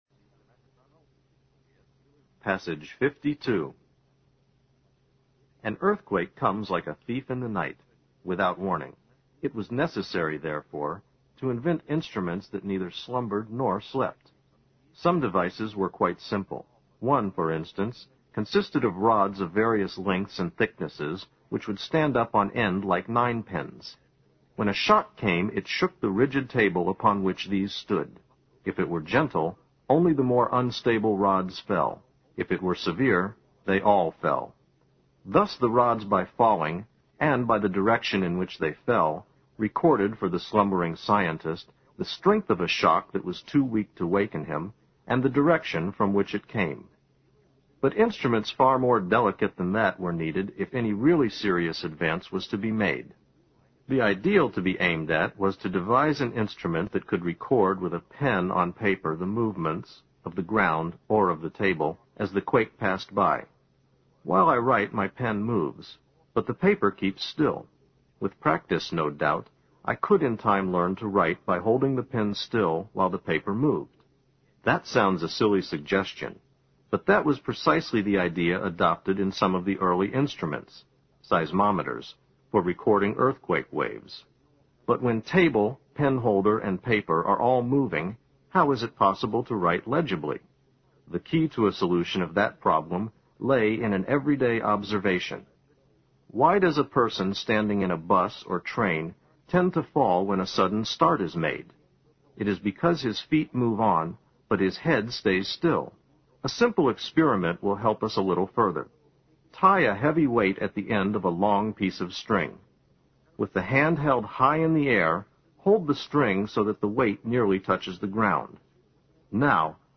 新概念英语85年上外美音版第四册 第52课 听力文件下载—在线英语听力室